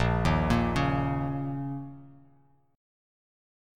A#mbb5 chord